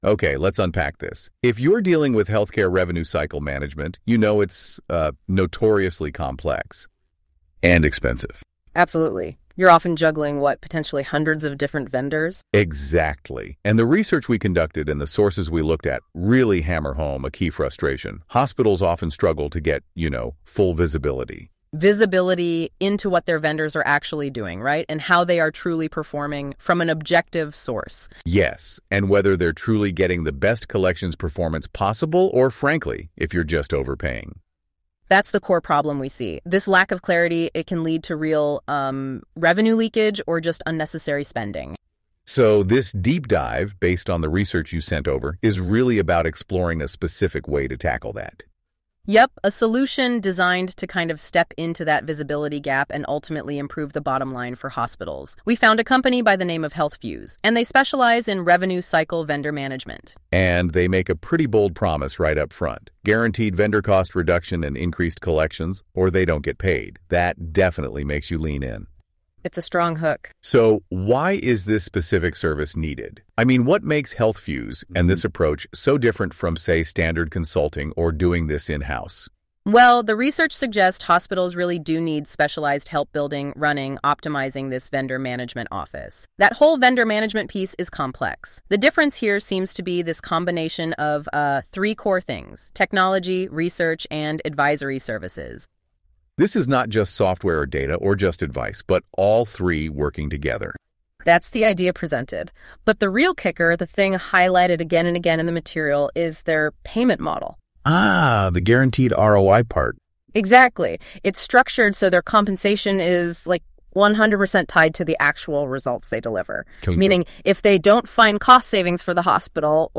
Healthfuse Interview - Healthfuse